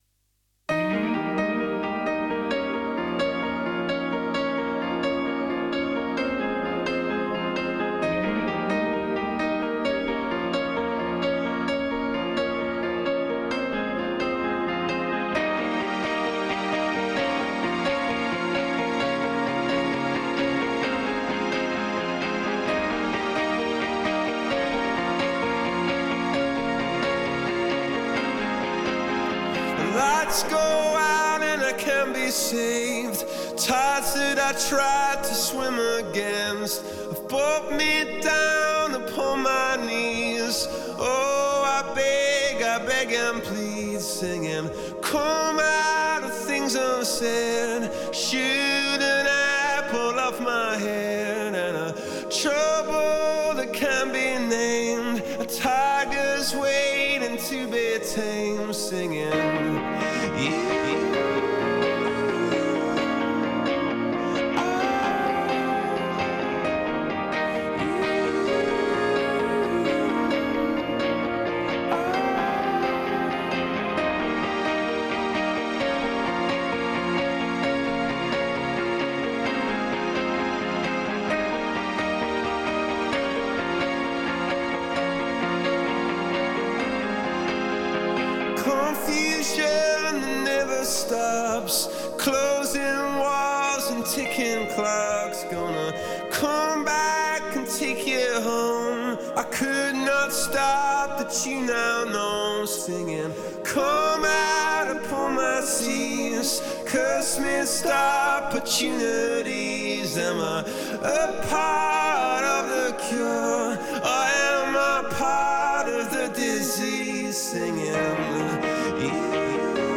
These are my mixes!!!
No Bass and Drums